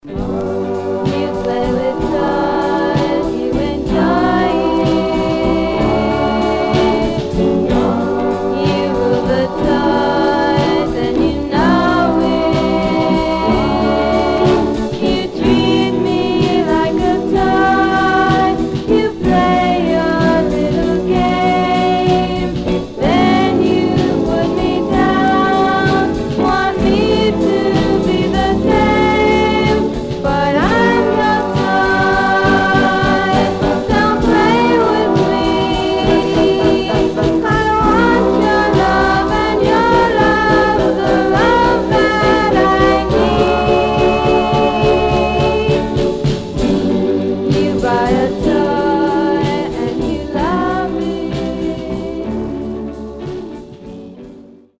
Some surface noise/wear Stereo/mono Mono
Teen